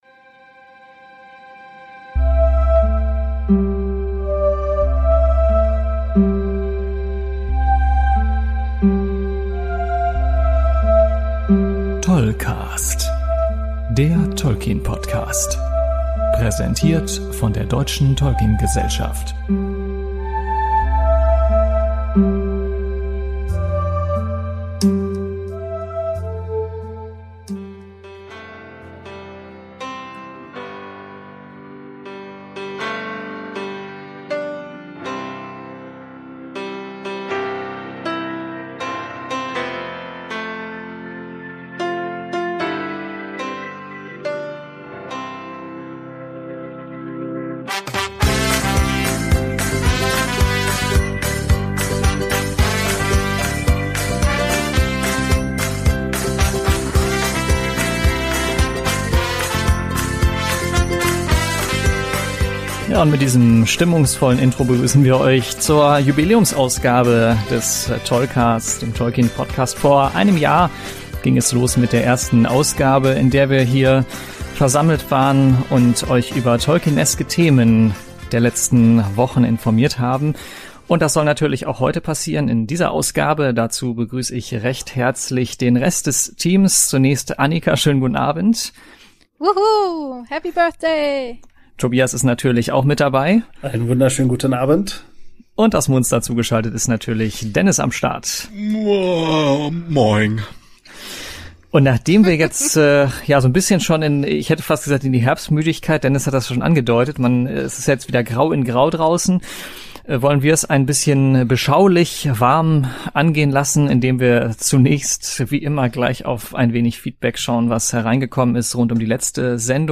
Es ist wieder eine rundum volle Sendung geworden mit allen fünf Beteiligten.